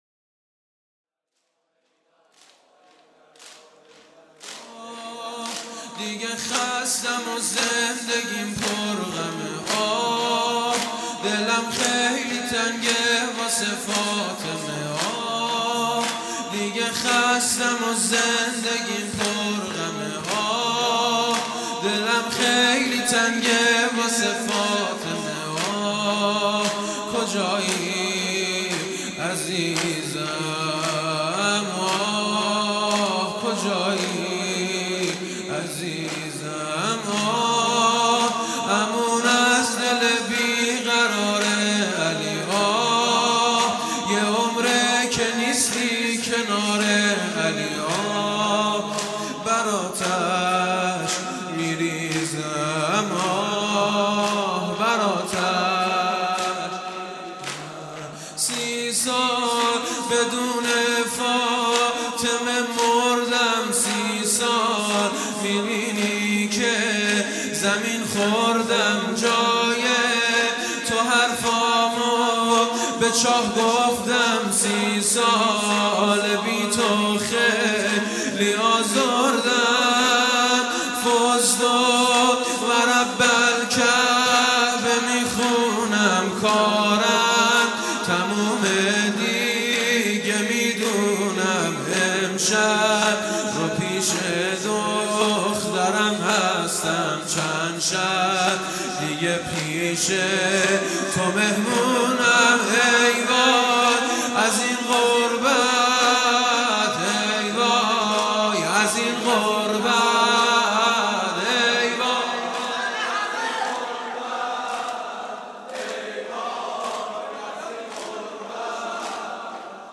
سی سال بدون فاطمه|شب ۱۹ رمضان ۹۸